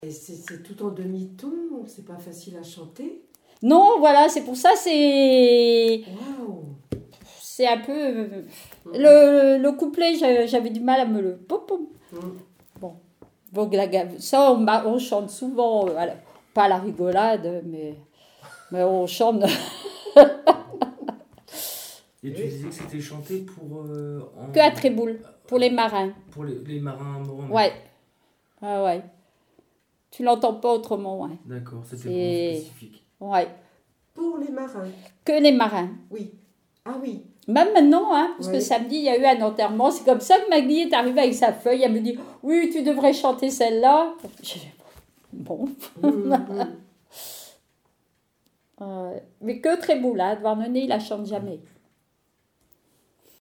témoignages et bribes de chansons
Témoignage